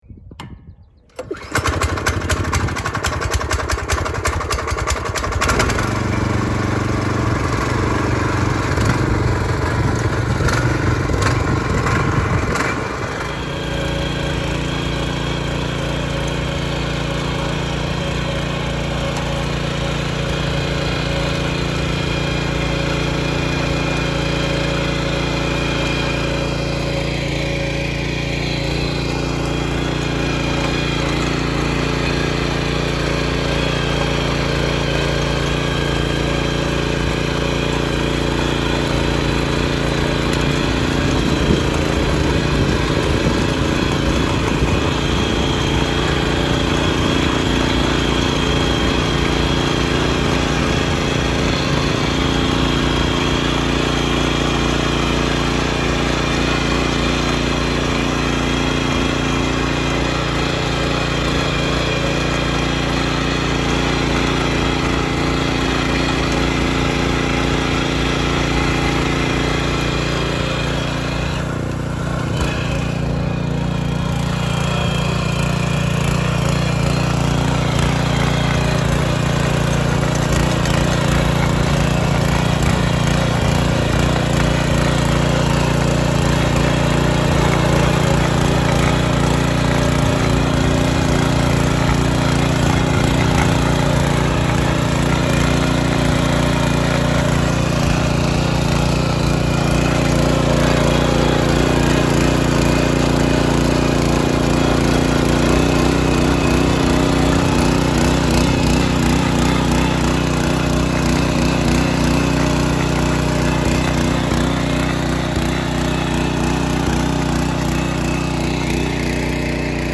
Мотоблок Stihl: завели вручную, шворкой, шнурком, немного культивировали и заглушили